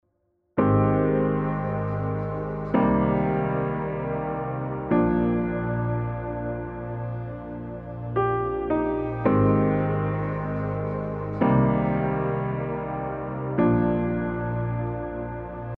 My Piano